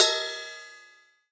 S_ride1Bell_1.wav